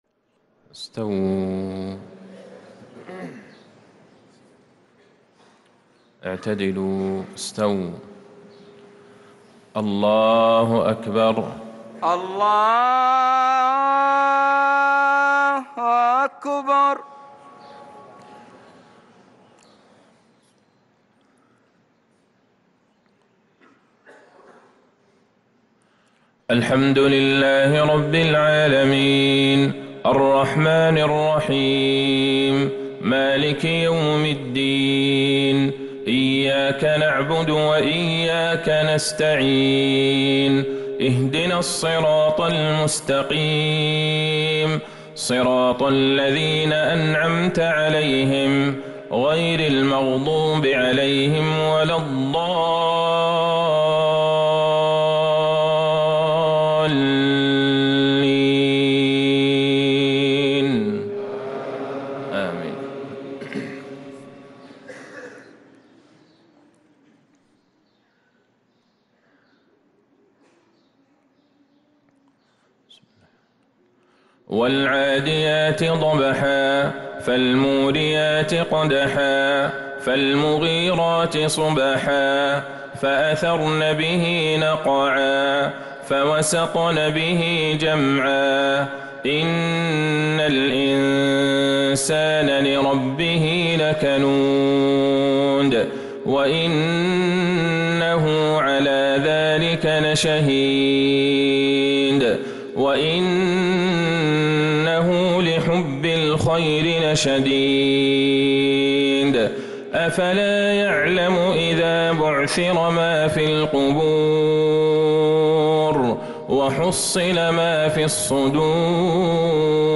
صلاة المغرب للقارئ عبدالله البعيجان 20 جمادي الآخر 1445 هـ
تِلَاوَات الْحَرَمَيْن .